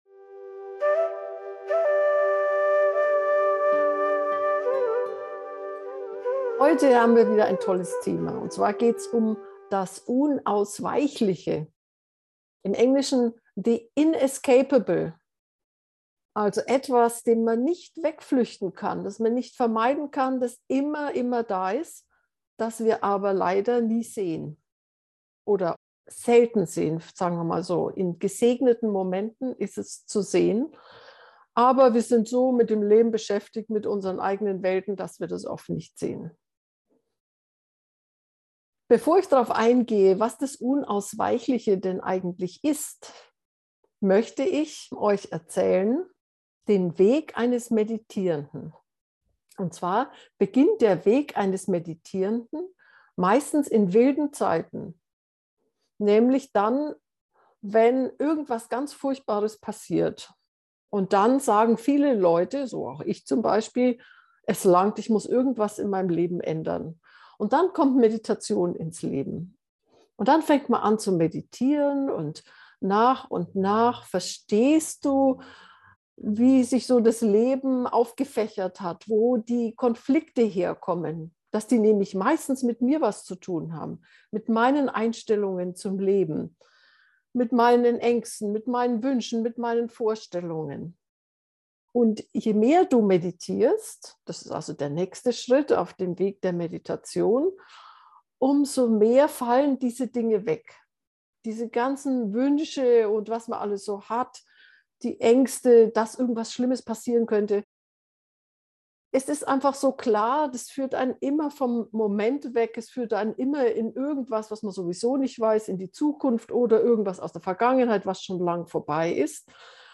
unausweichliche-harmonie-gefuehrte-meditation